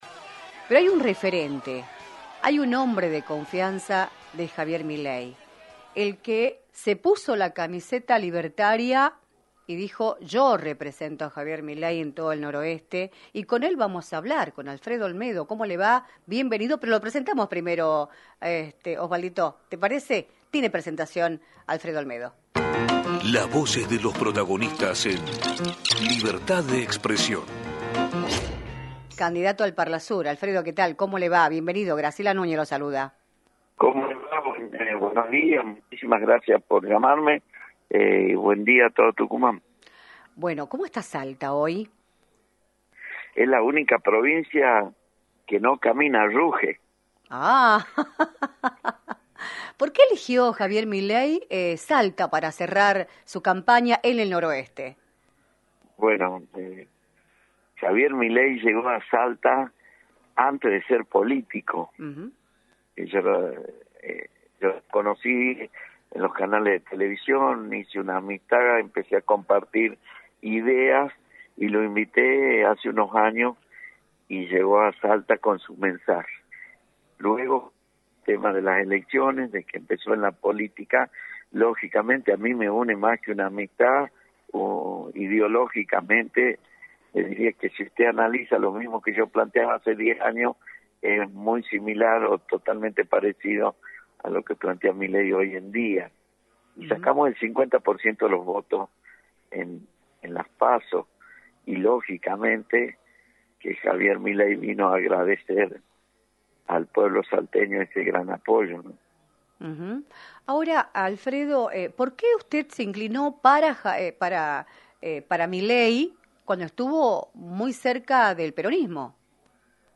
Alfredo Olmedo, candidato al Parlasur en Salta por La Libertad Avanza y referente del Liberalismo en el Norte Argentino, analizó en “Libertad de Expresión”, por la 106.9, la previa de las elecciones presidenciales del próximo 22 de octubre.